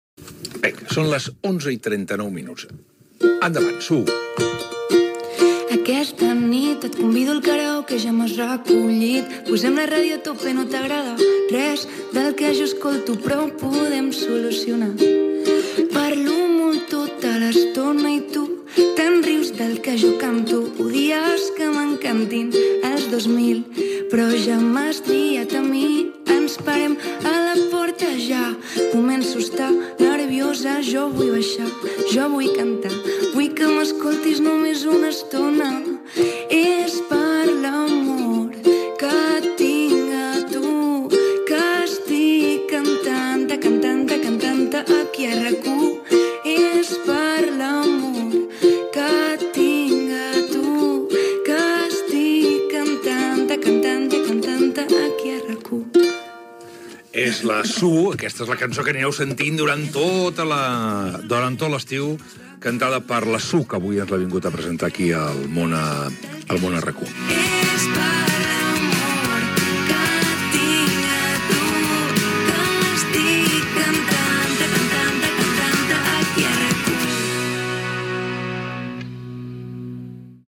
interpreta en directe la cançó de l'estiu
Info-entreteniment